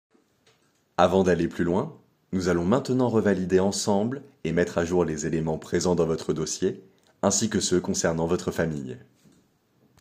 Voix off
Bandes-son
25 - 40 ans - Basse